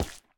Minecraft Version Minecraft Version 1.21.5 Latest Release | Latest Snapshot 1.21.5 / assets / minecraft / sounds / block / resin / resin_place4.ogg Compare With Compare With Latest Release | Latest Snapshot
resin_place4.ogg